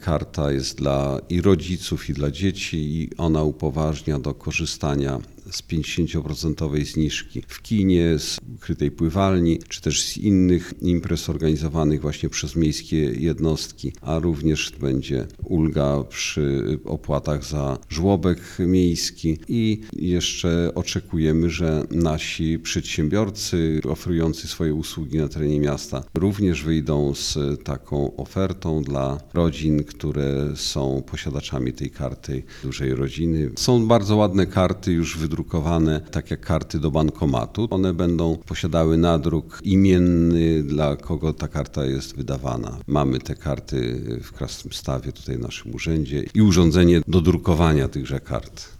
- Karty będą przysługiwały rodzinom, które mają troje i więcej dzieci - przypomina burmistrz Krasnegostawu Andrzej Jakubiec.